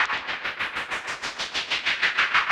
RI_RhythNoise_95-03.wav